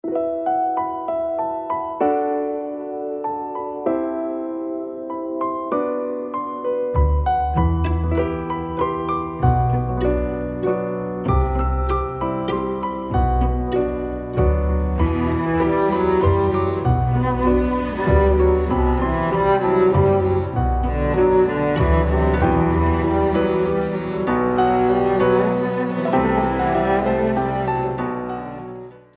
رینگتون (بی کلام) بسیار زیبا و رمانتیک